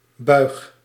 Ääntäminen
Ääntäminen Tuntematon aksentti: IPA: /bœy̯x/ Haettu sana löytyi näillä lähdekielillä: hollanti Käännöksiä ei löytynyt valitulle kohdekielelle.